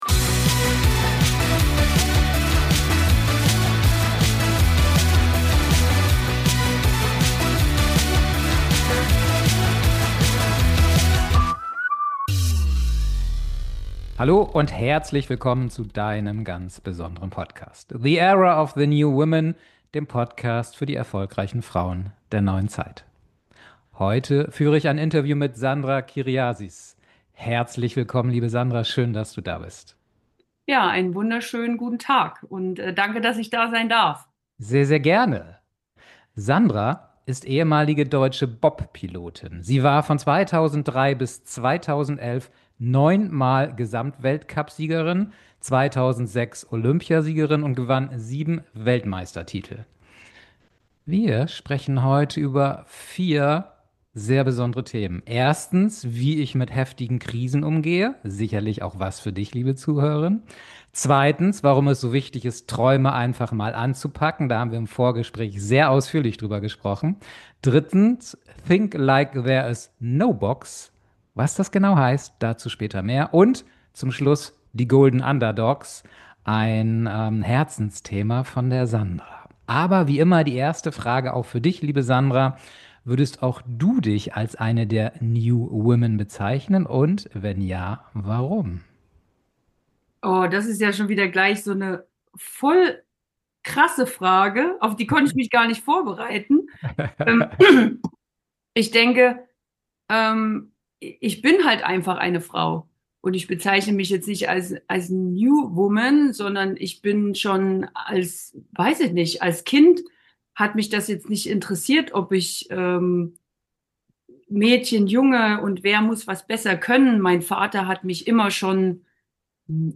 Das Interview mit Sandra Kiriasis. ~ The Era of the New Women Podcast
Und trotzdem flossen die Tränen schon in den ersten Minuten.